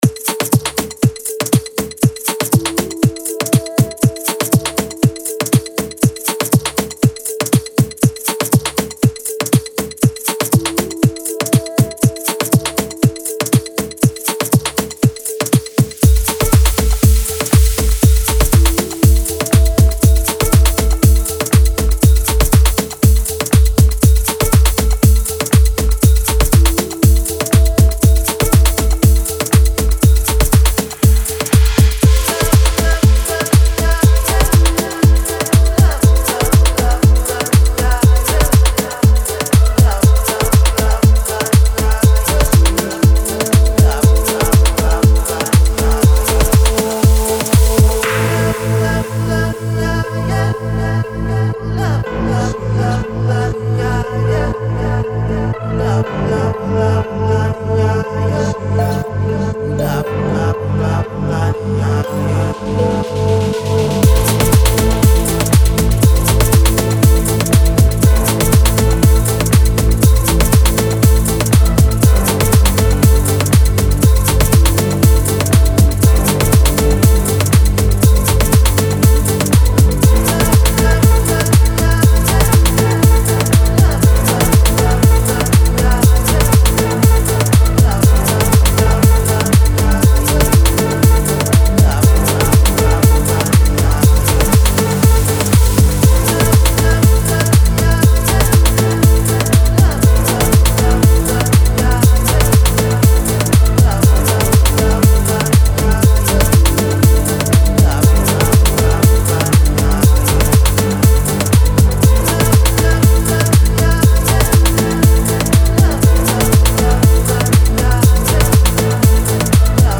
Deep House House
1 Full Studio Mastered Project (as heard in the demo)